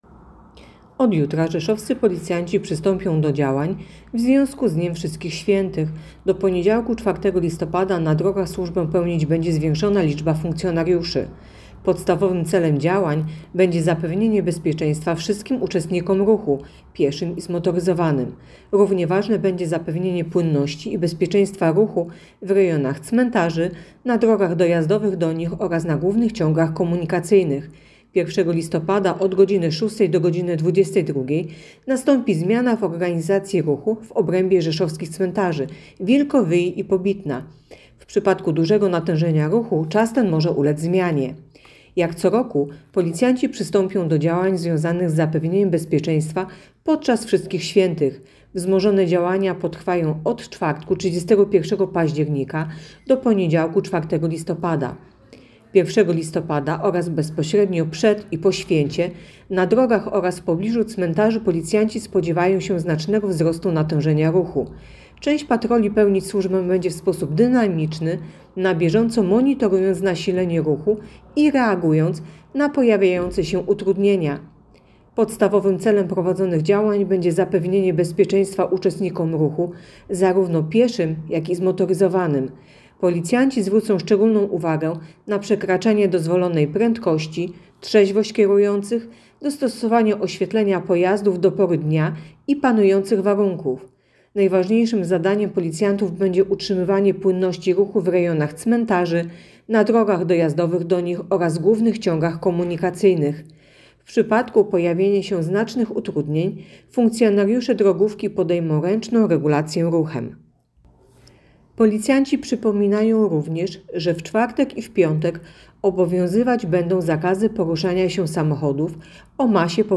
Opis nagrania: Nagranie informacji pt. Policyjne działania podczas Wszystkich Świętych.